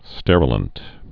(stĕrə-lənt)